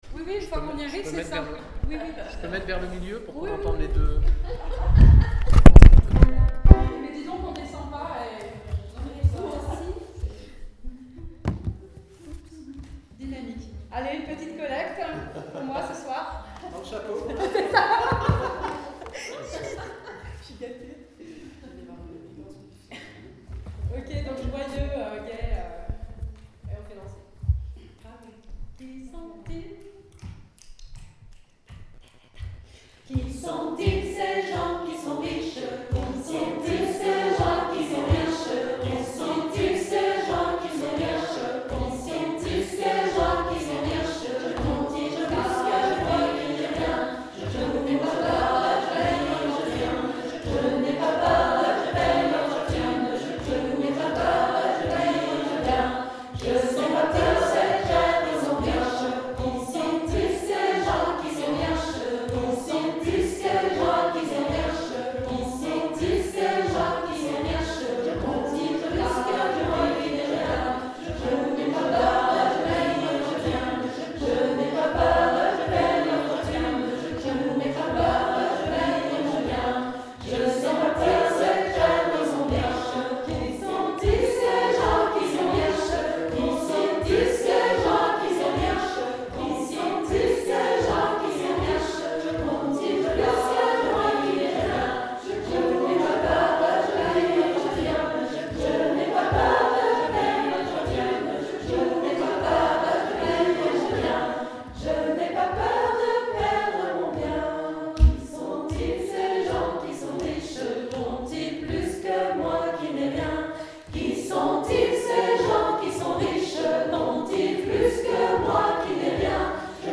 Une petite ritournelle du répertoire traditionnel, qu’on peut utiliser par exemple en intro pour lancer une autre scottish…
–> Un enregistrement en canon, lors d’un stage